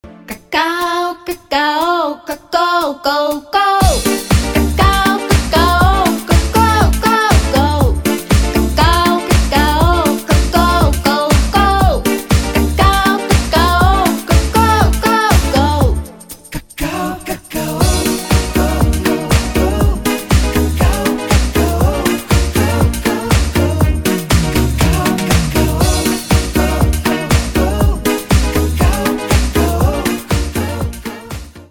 Музыка на Звонок 80х-90х, Громкие рингтоны